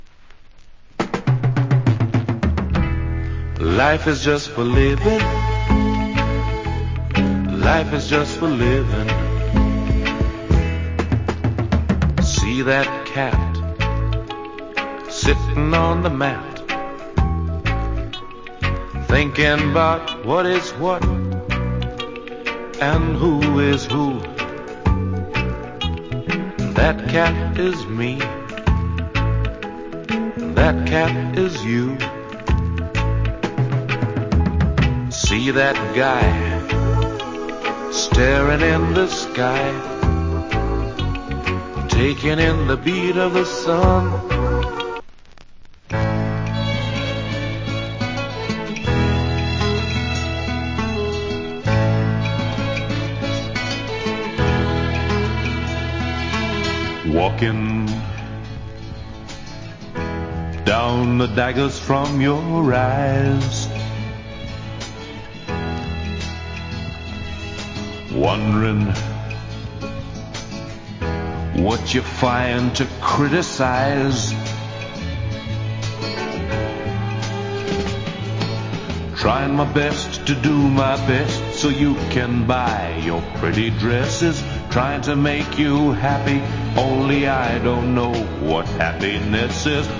Nice Soulful Reggae Vocal.